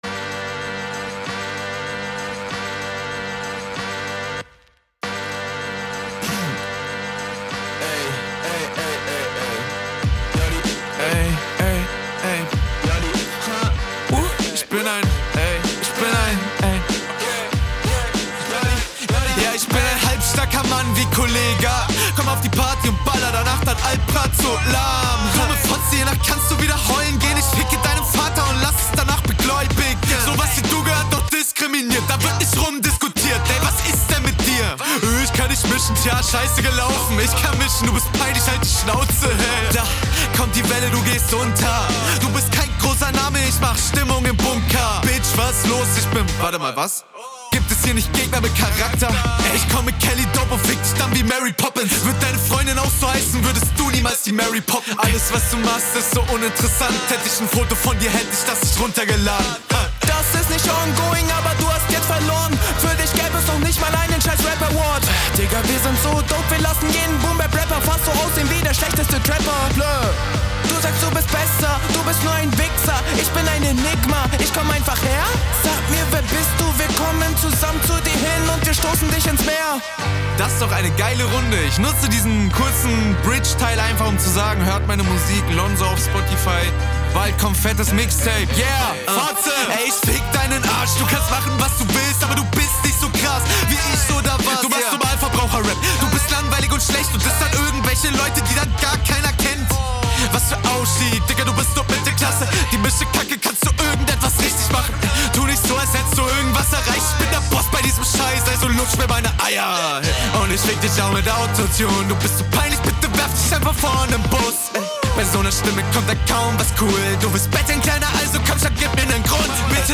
Geht gut nach Vorne!